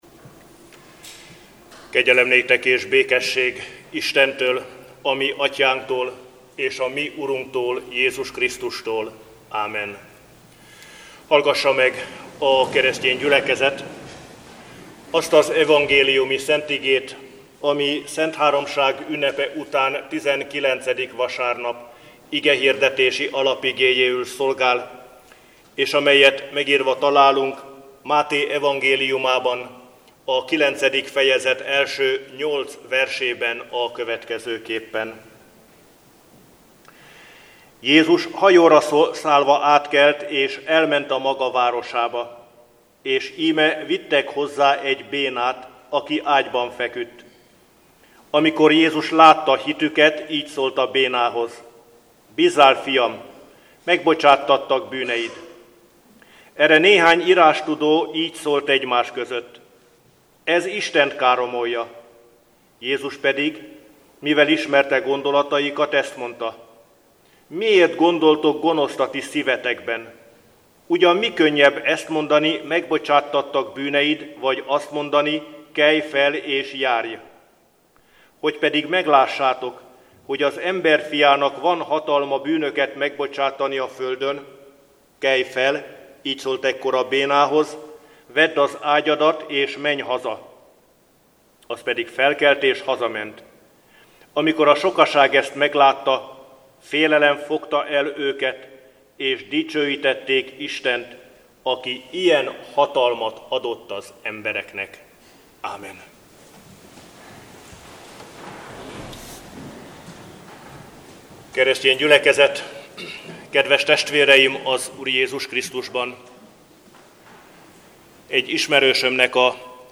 Igehirdetések